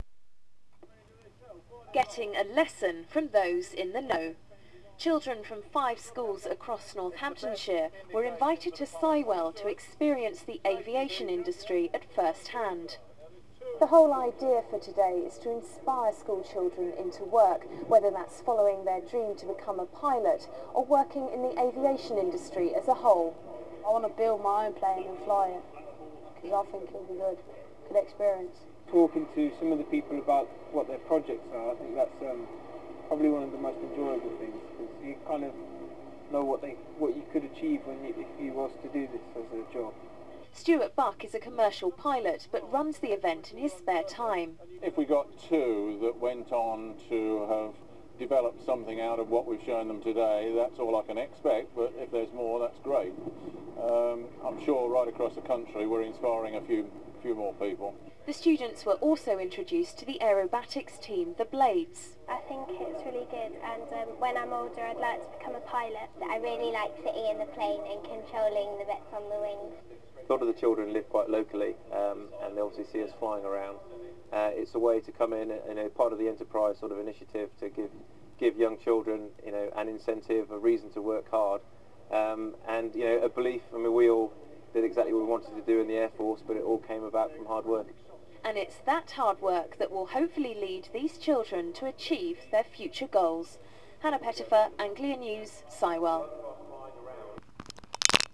Anglia TV Report:-